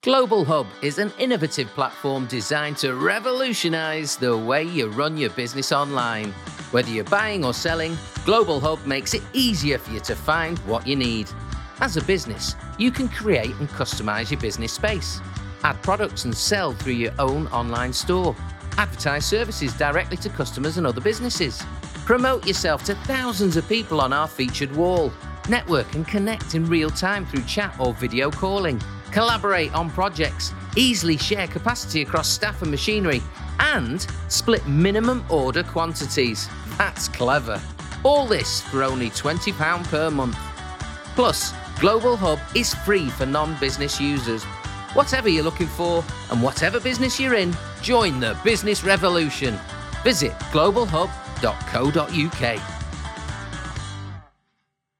Northern. Light, energetic and comic. Great sense of fun. Dollops of 'Manc' irony!